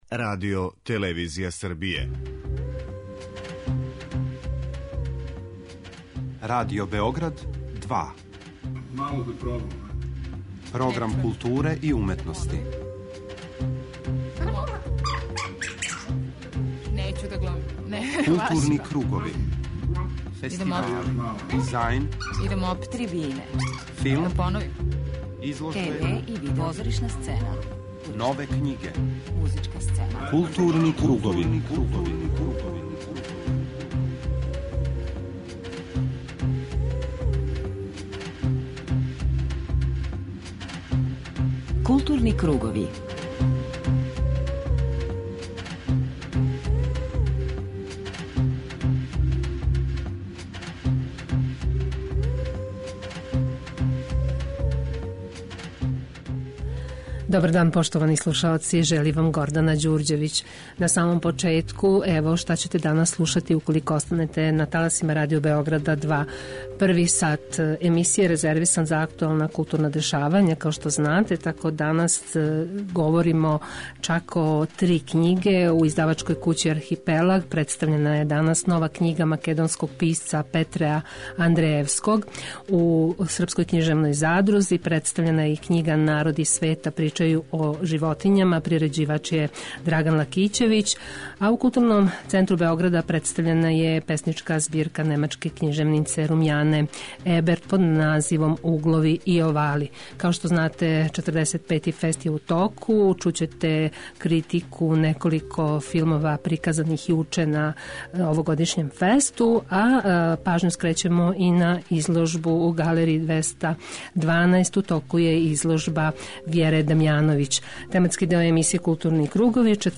преузми : 41.18 MB Културни кругови Autor: Група аутора Централна културно-уметничка емисија Радио Београда 2.